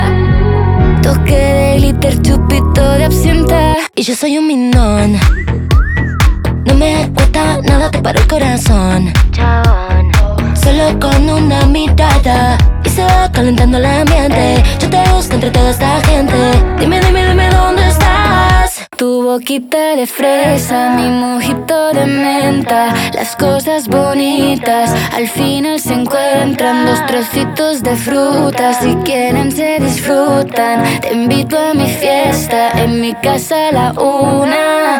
Жанр: Поп
# Pop in Spanish